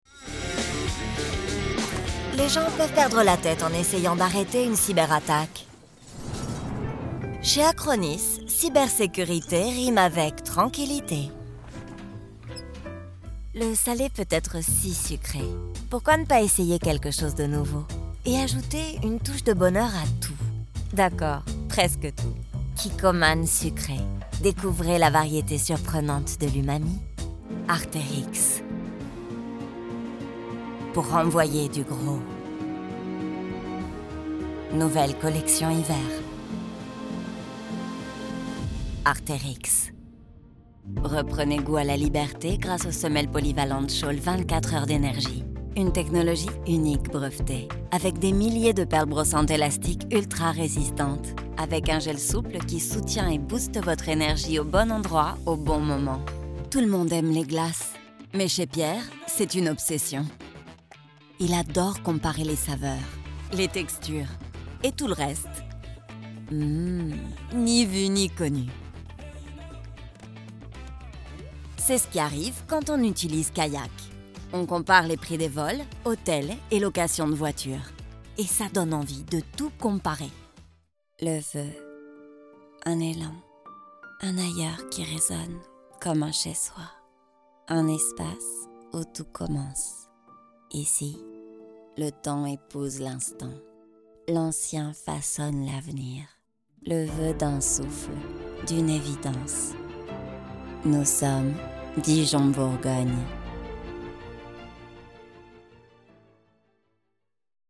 Hire Concerned Voice Actors For Your Project | Voice Crafters
Looking for a voice that communicates genuine interest in your audience?